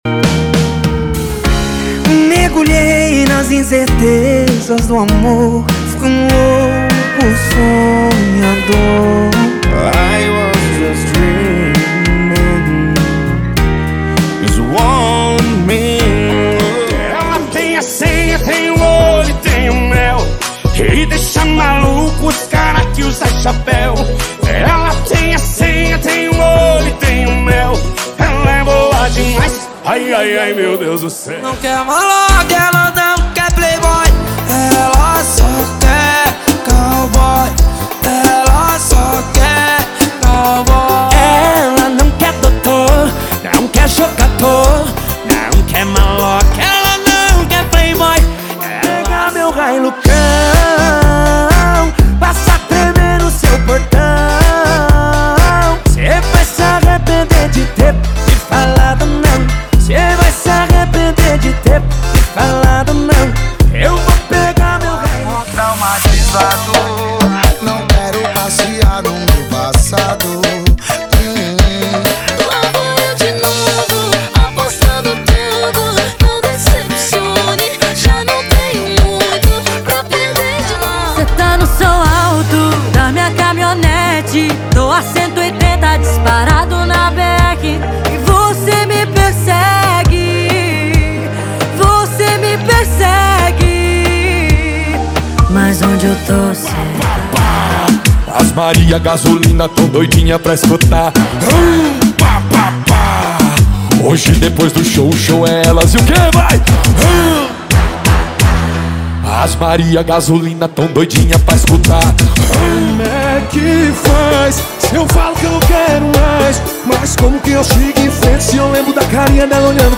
• Sertanejo = 65 Músicas
• Sem Vinhetas
• Em Alta Qualidade